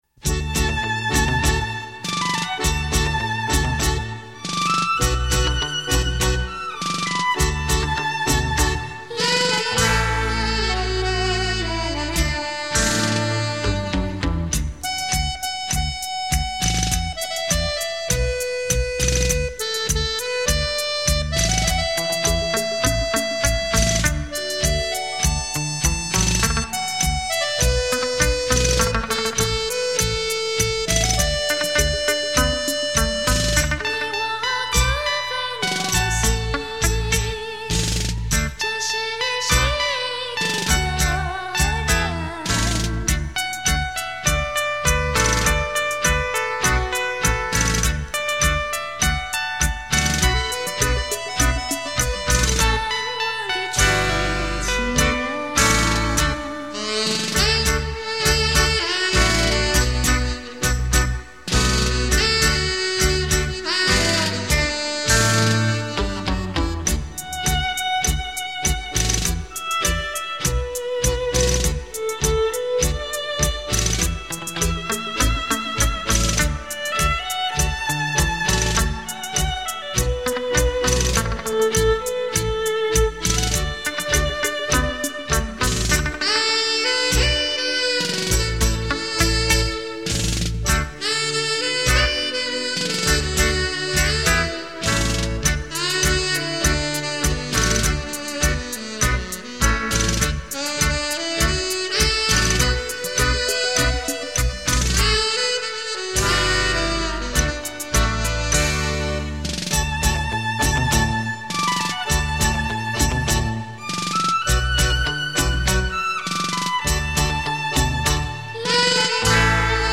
重新诠释并融入舞曲风格保证令人耳目一新